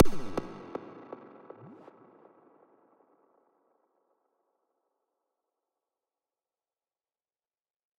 描述：Effected snare hit with delay, 120 bpm
声道立体声